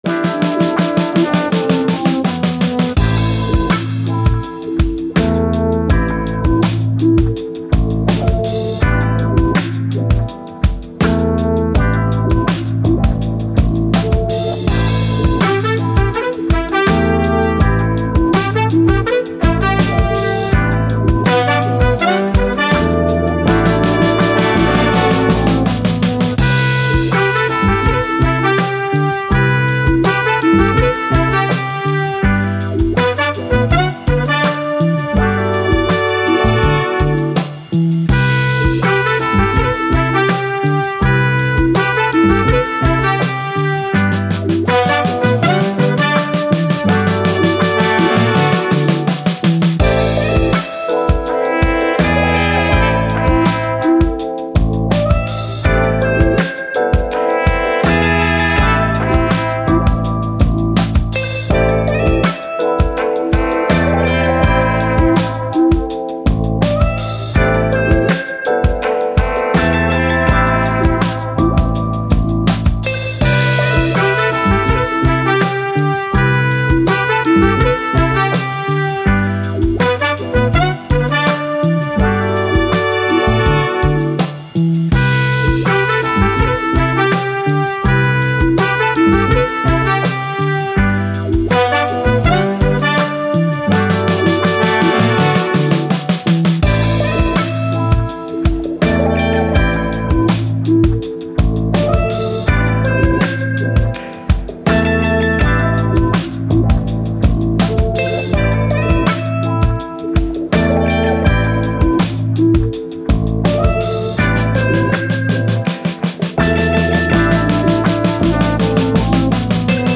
Soul, Hip Hop, Vintage, Vibe, Positive, Happy